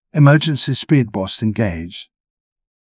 emergency-speedboost-engaged.wav